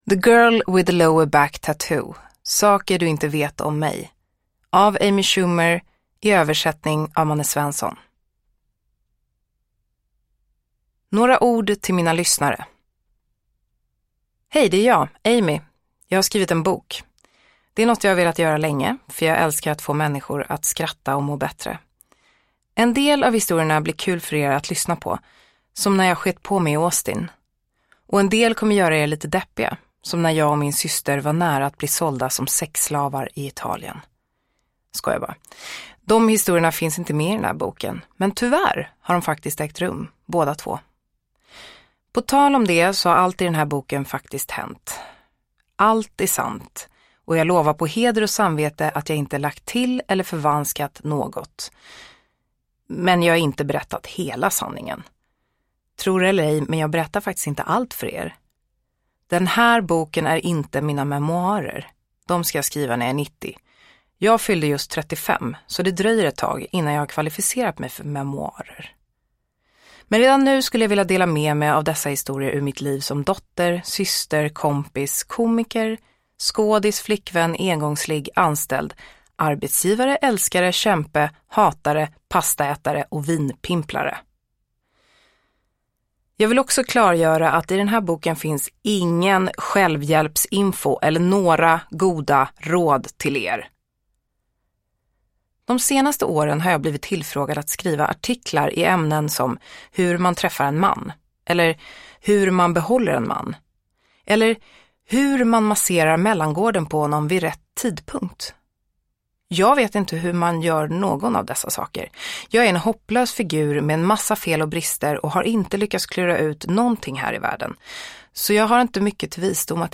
Uppläsare: Josephine Bornebusch